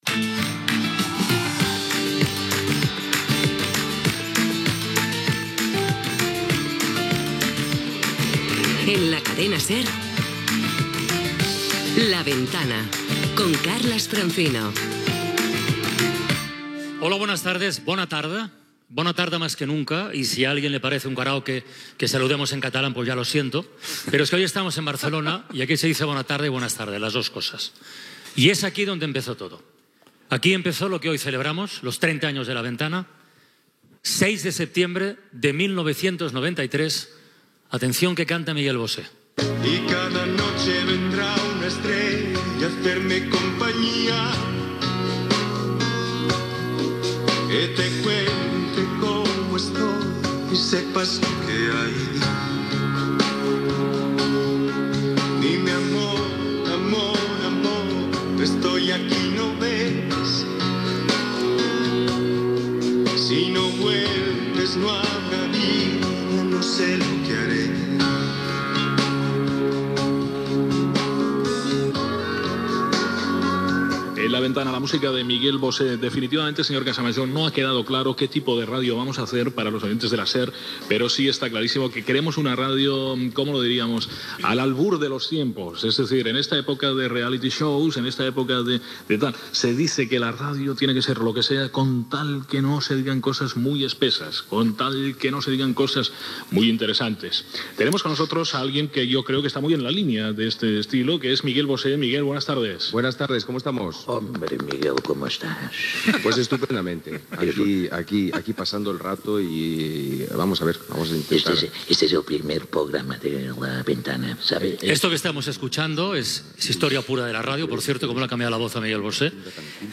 e2d33c40cd50b125498c956ae634829c90284dcb.mp3 Títol Cadena SER Emissora Ràdio Barcelona FM Cadena SER Titularitat Privada estatal Nom programa La ventana Descripció Programa especial, fet des del Teatre Victòria de Barcelona, en complir-se 30 anys del programa.